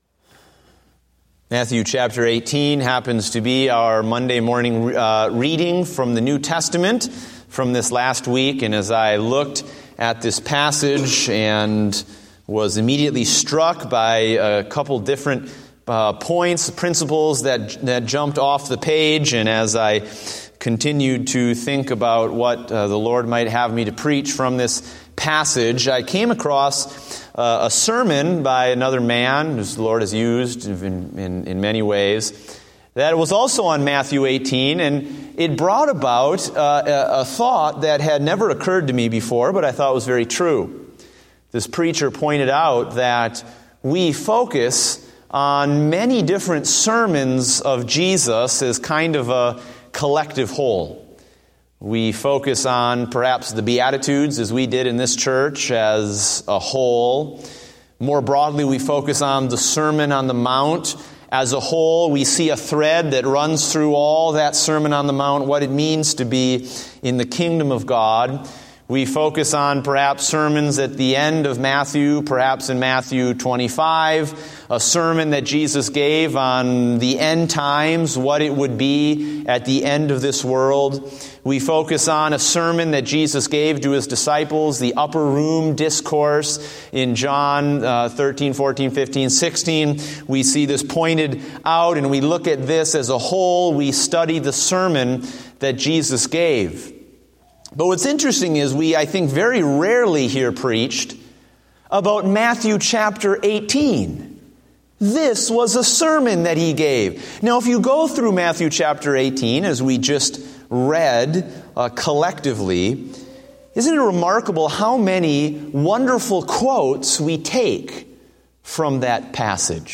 Date: January 31, 2016 (Evening Service)